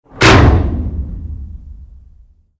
AA_drop_safe_miss.ogg